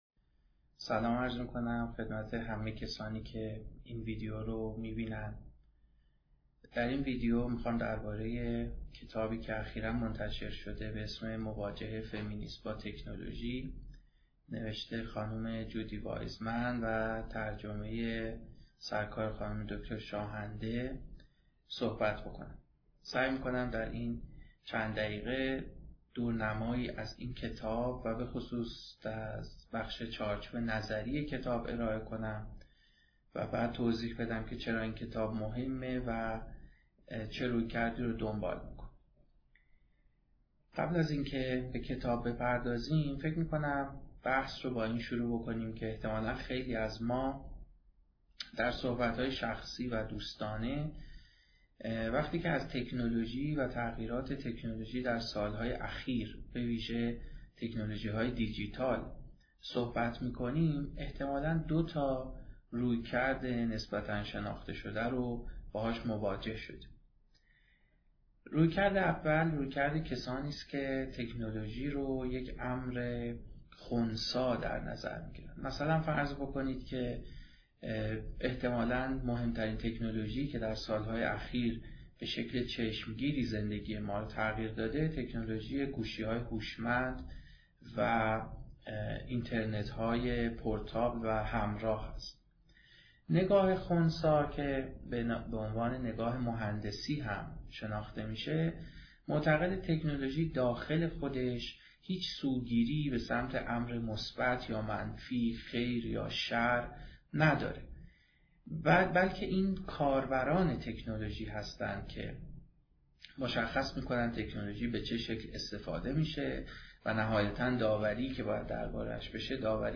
در مرکز فرهنگی شهرکتاب برگزار شده است